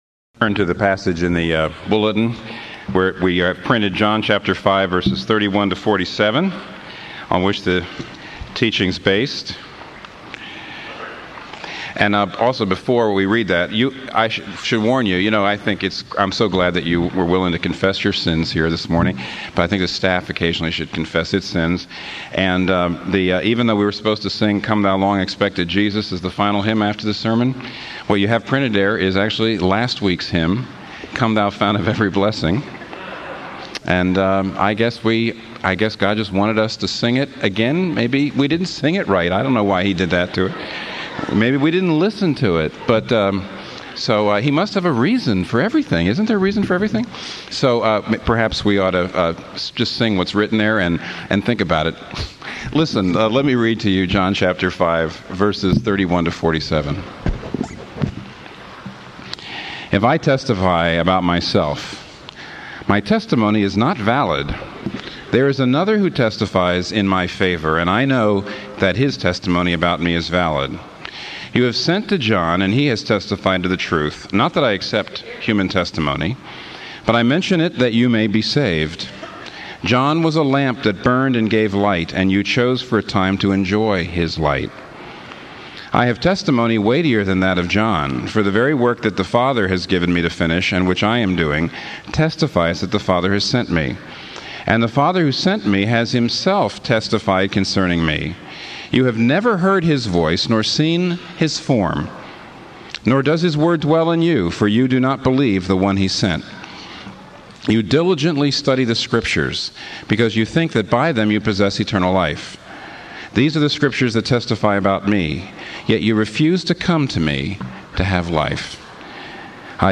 The following sermons are in MP3 format.